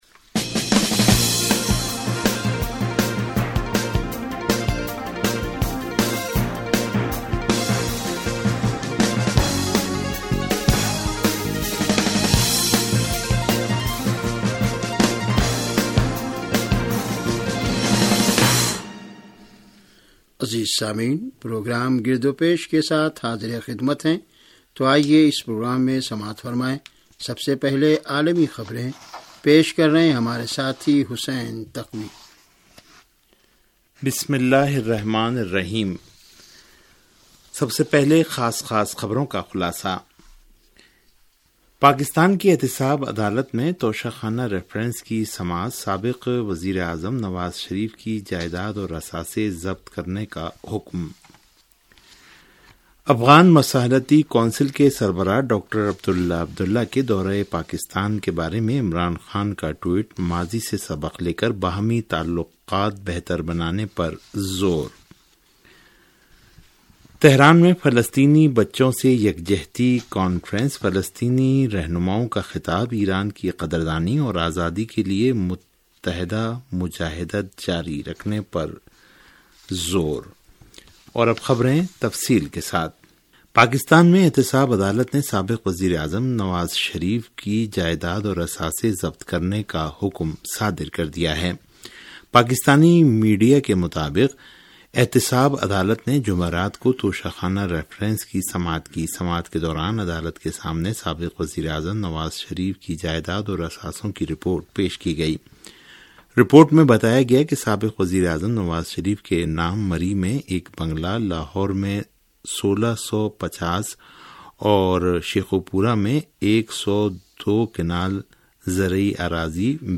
ریڈیو تہران کا سیاسی پروگرام - گرد و پیش